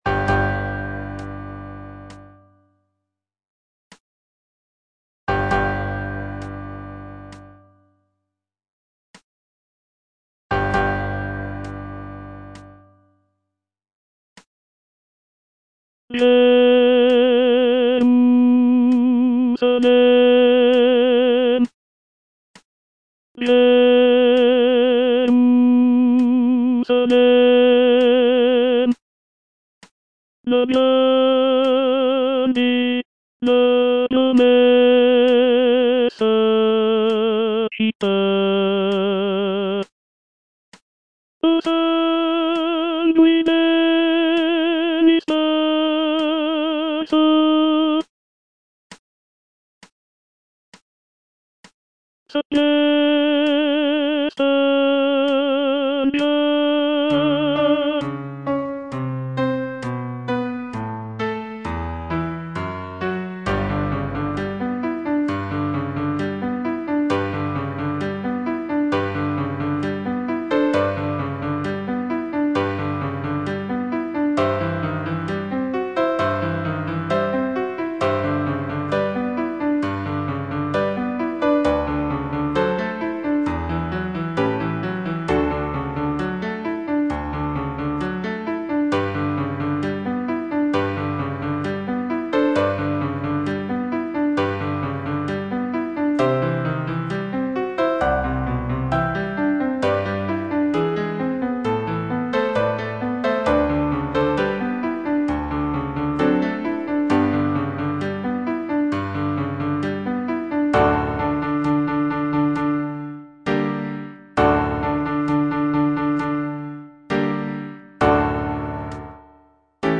The powerful and emotional choral writing, along with the grand orchestration, creates a sense of reverence and anticipation for the epic quest ahead.